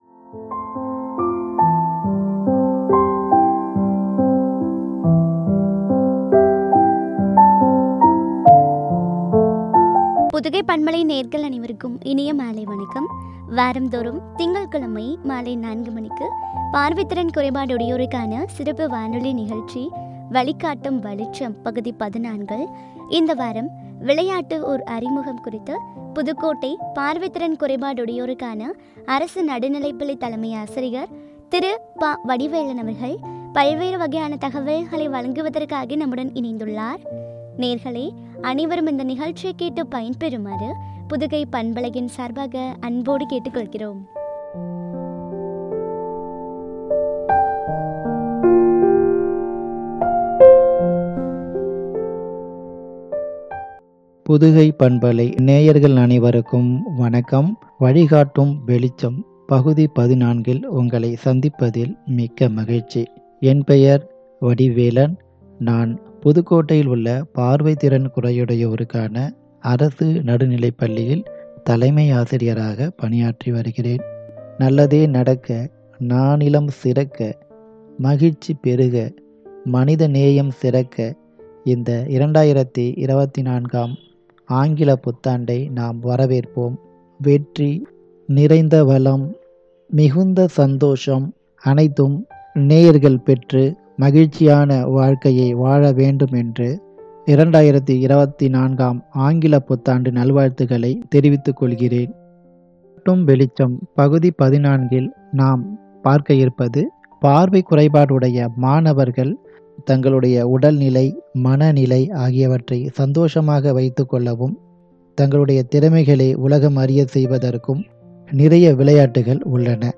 பார்வை திறன் குறையுடையோருக்கான சிறப்பு வானொலி நிகழ்ச்சி
” விளையாட்டு ஓர் அறிமுகம் குறித்து வழங்கிய உரையாடல்.